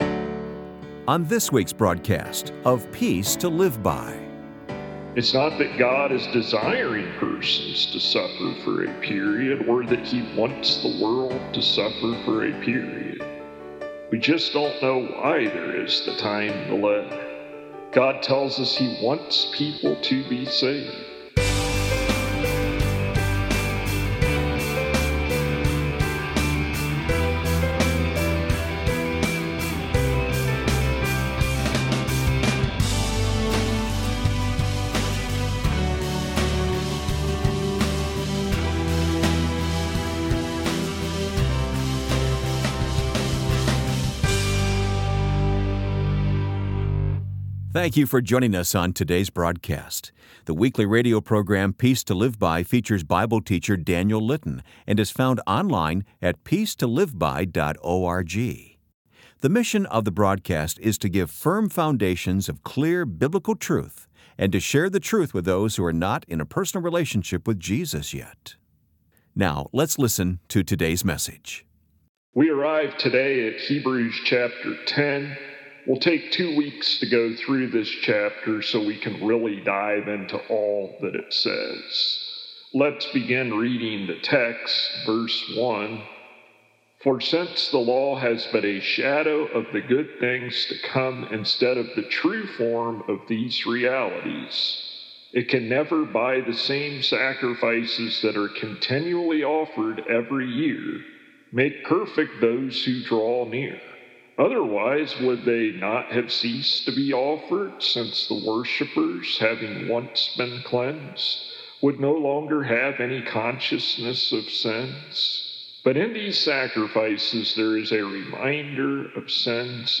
For full sermons without edits for time, tap here to go to downloads page. [Transcript represents full sermon's text] We arrive today at Hebrews chapter 10.